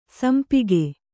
♪ sampige